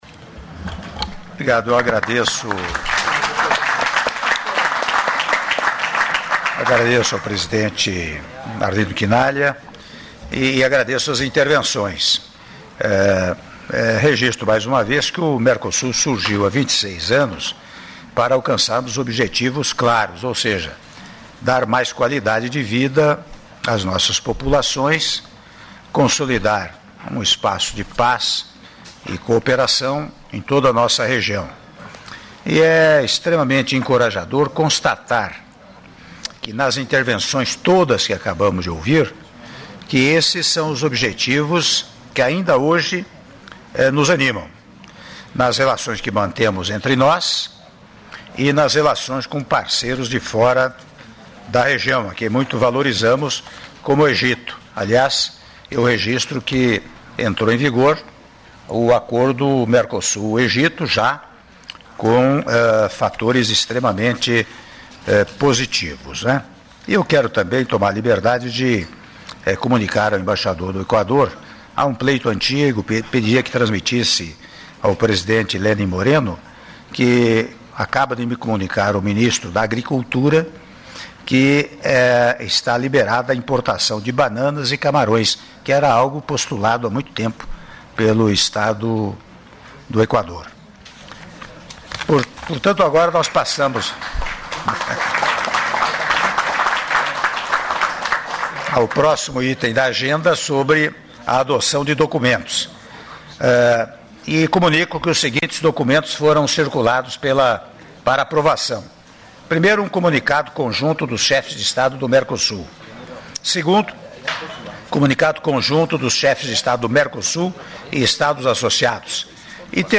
Áudio do Discurso do Presidente da República, Michel Temer, no encerramento da LI Cúpula de Chefes de Estado do Mercosul e Estados Associados - Palácio Itamaraty (04min12s)